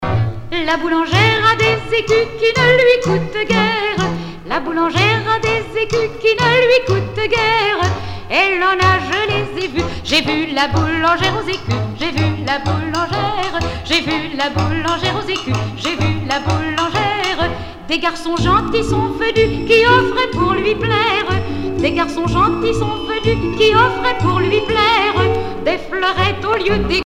Rondes enfantines à baisers ou mariages
Pièce musicale éditée